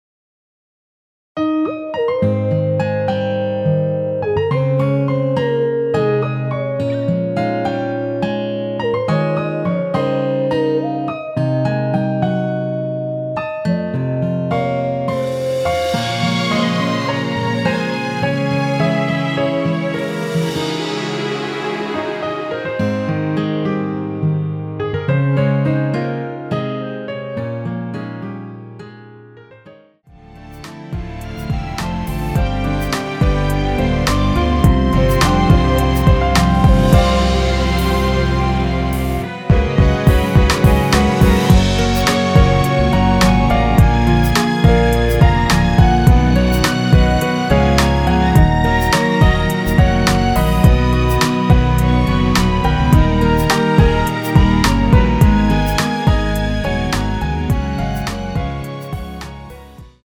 여성분이 부르실 수 있는키의 MR입니다.
원키에서(+5)올린 멜로디 포함된 MR입니다.
Ab
앞부분30초, 뒷부분30초씩 편집해서 올려 드리고 있습니다.
중간에 음이 끈어지고 다시 나오는 이유는